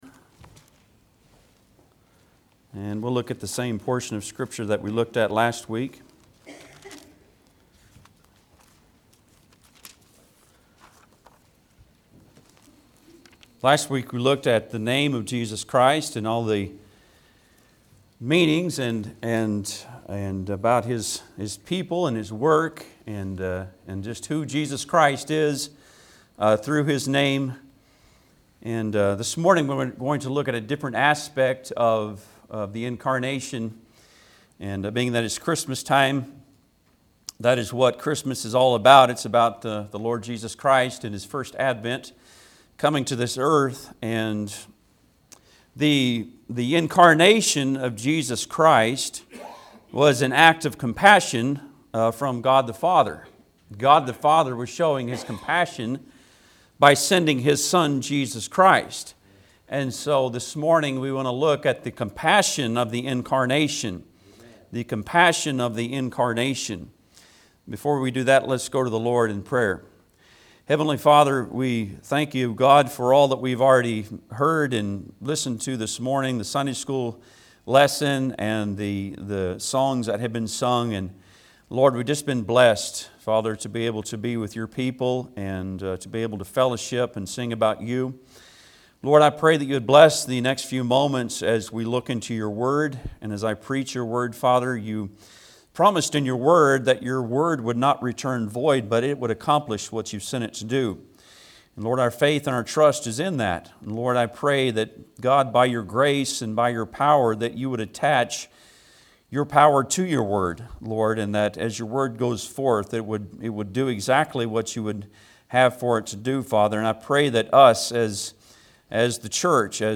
Matthew 1:18-25 Service Type: Sunday am Bible Text